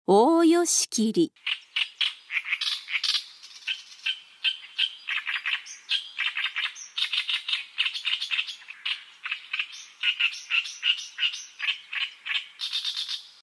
オオヨシキリ
【鳴き声】普段は「ジョッ、ジョッ」と鳴く。繁殖期のオスは、草の高い位置にとまり、「ギョギョギョ ギョギチ ギョギチ ギョギョ ギョギョ ギギギギギ」と大声でさえずる。
オオヨシキリの鳴き声（音楽：220KB）